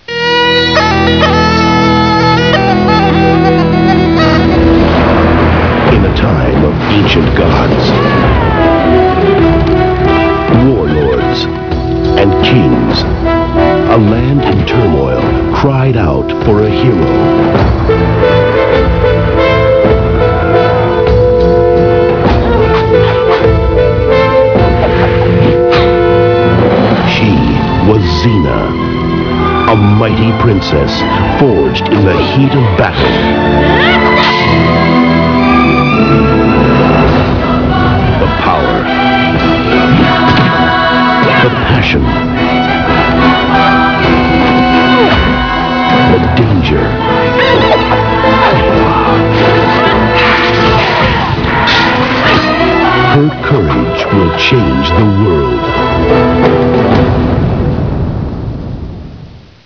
TV Theme